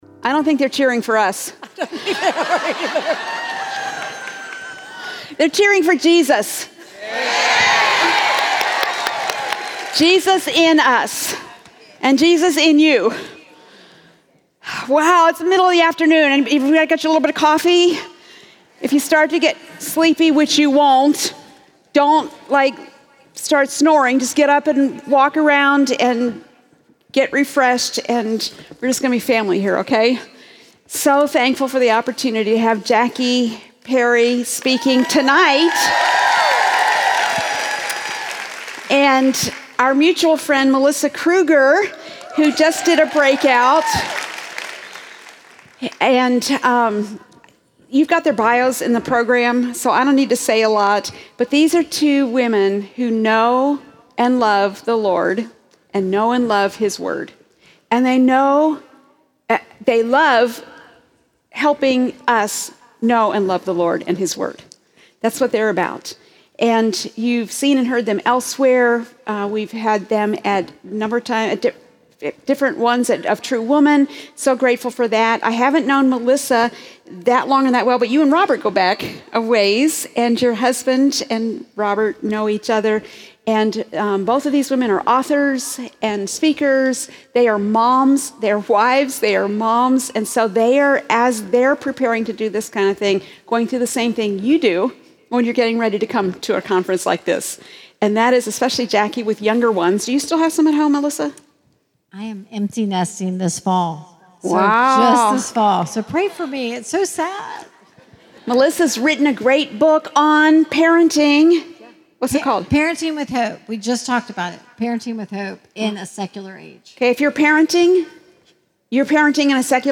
Don’t Be Swayed | True Woman '25 | Events | Revive Our Hearts